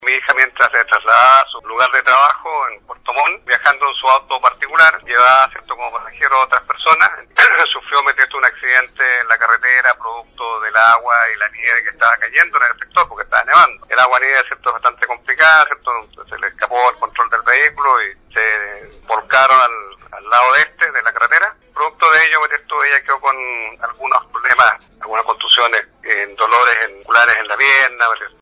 En diálogo con Radio Sago, el Alcalde de Osorno, Jaime Bertín – padre de la Seremi de Agricultura – comentó detalles de este accidente de transito recalcando que la más complicada fue la autoridad del agro Pamela Bertín quien, producto de las lesiones, fue trasladada al Hospital Base de Osorno.